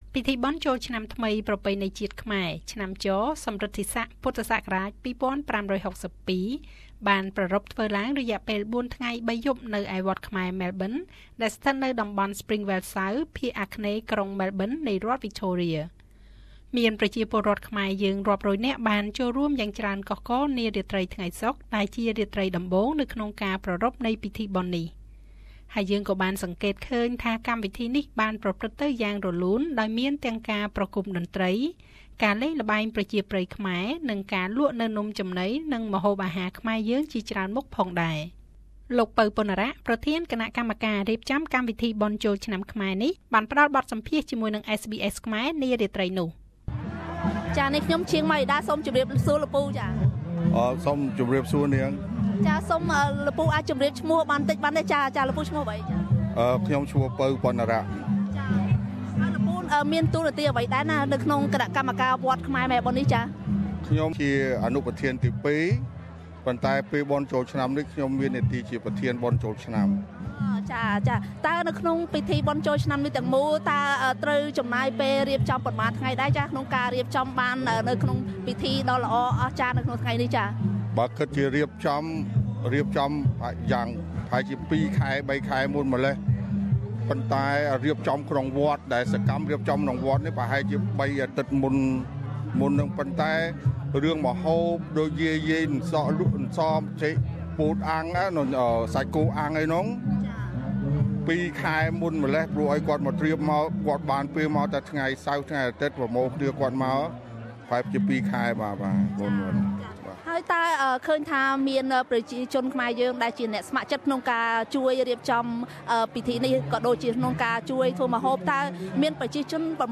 នេះជាបទសម្ភាសន៍ជាមួយនឹង គណៈកម្មការ ក៏ដូចជាអ្នកស្ម័គ្រចិត្តចូលរួមរៀបចំកម្មវិធីបុណ្យចូលឆ្នាំខ្មែរនេះ។